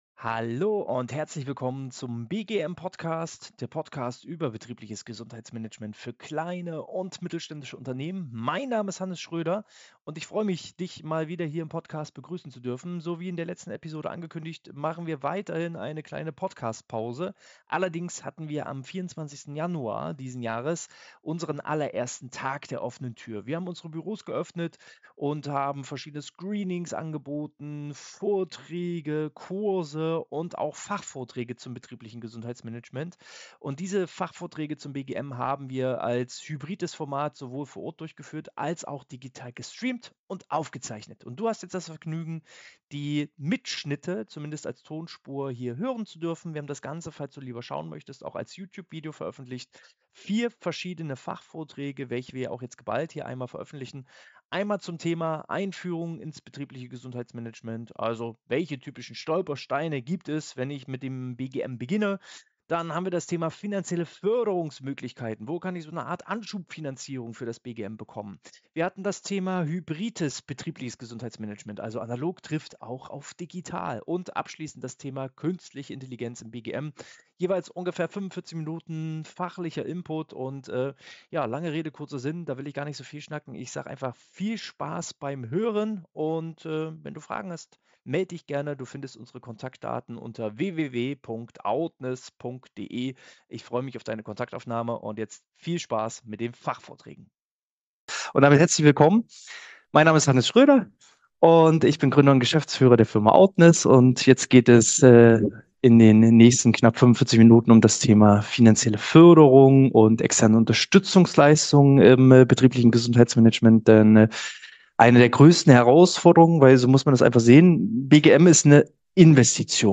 Fachvortrag: Finanzielle Förderung im BGM I outness ~ Betriebliches Gesundheitsmanagement Podcast